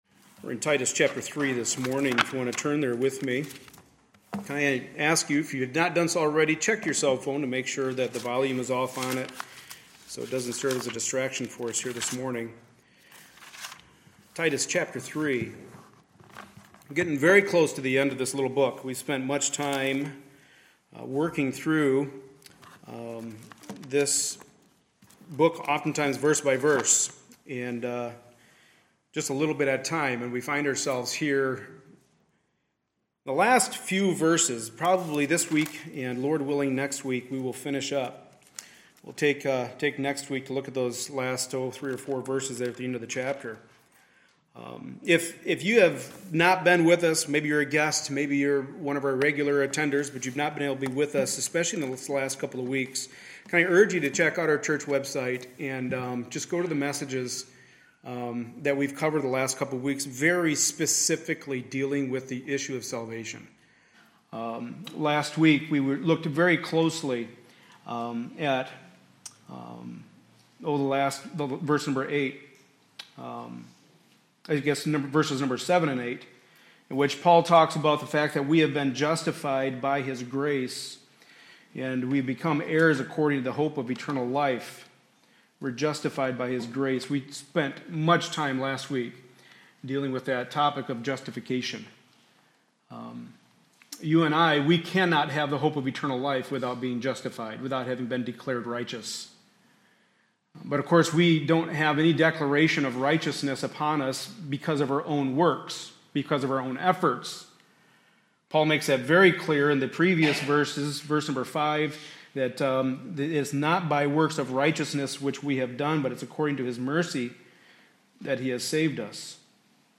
Passage: Titus 3:9-11 Service Type: Sunday Morning Service